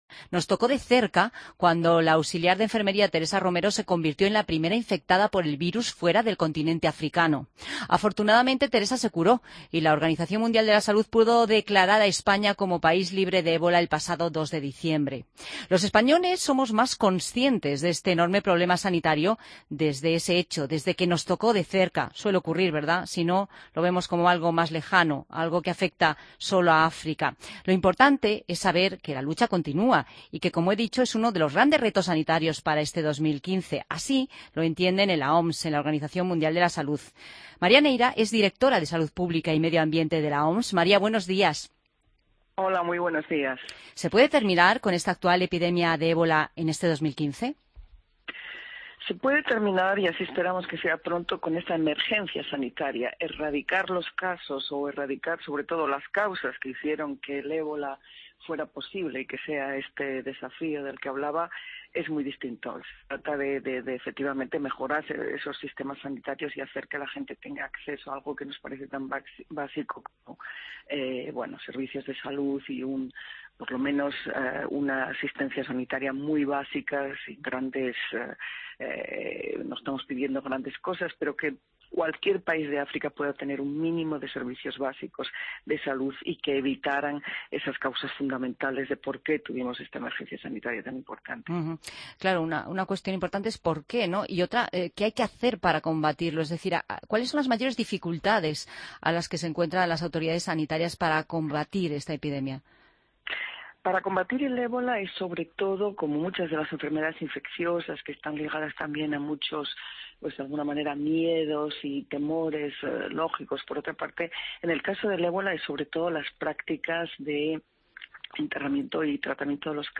AUDIO: Entrevista a María Neira en Fin de Semana COPE